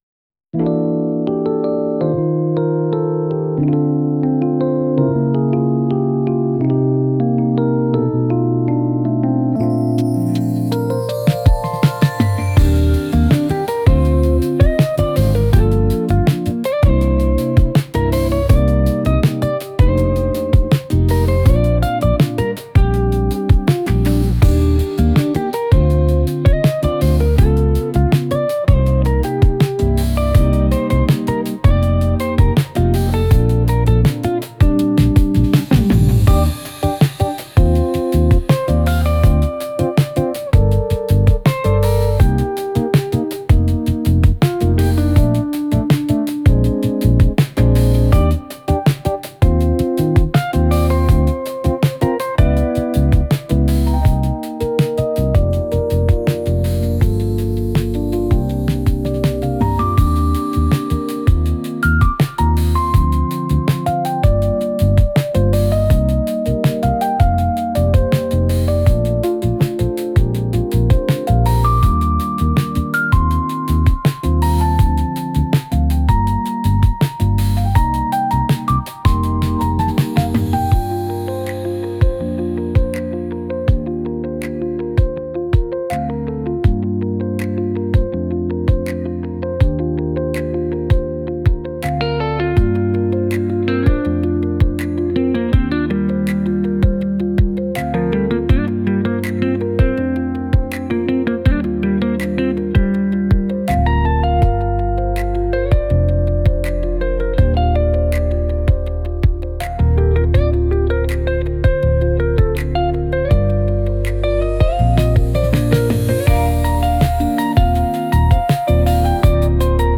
R&B おしゃれ 夜